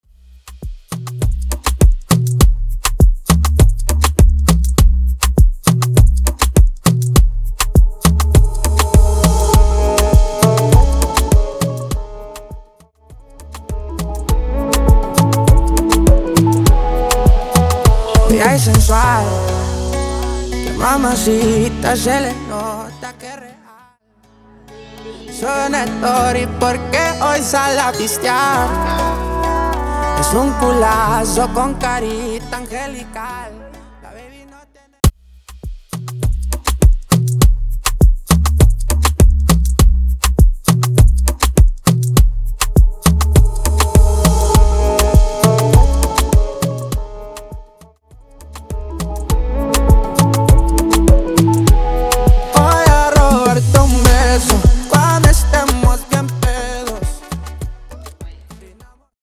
Intro Dirty, Coro Dirty